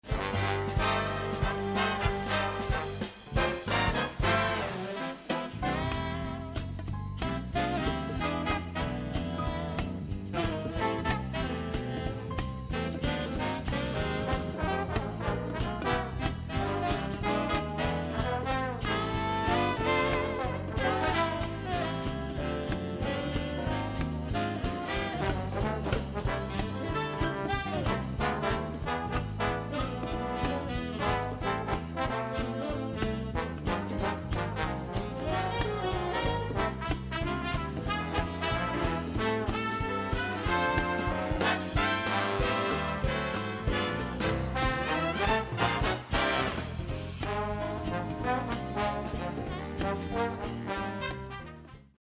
incredible swingin theme